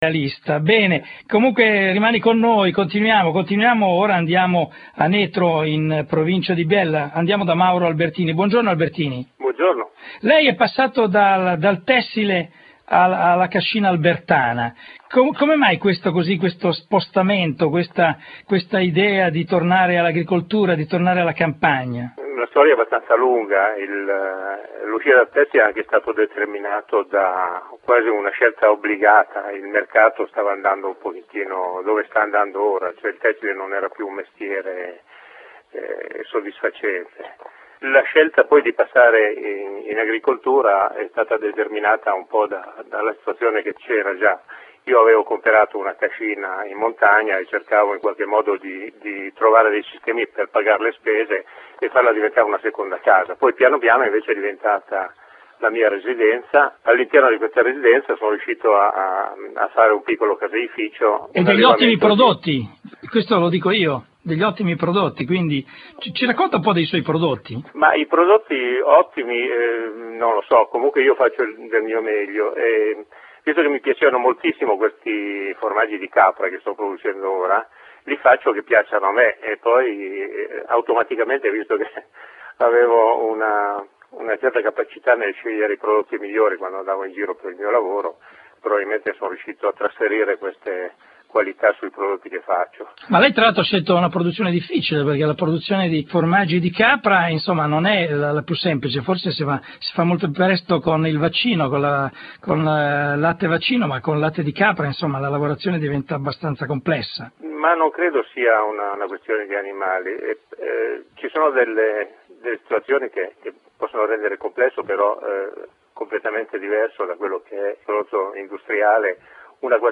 intervista su Radio24 "Il Gastronauta"